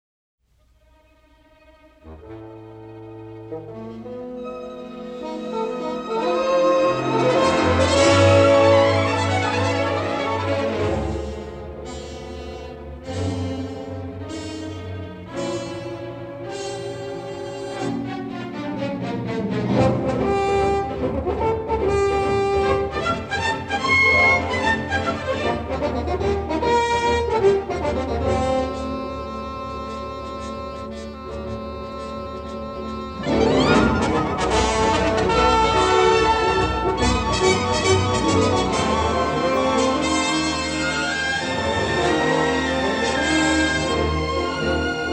in stereo sound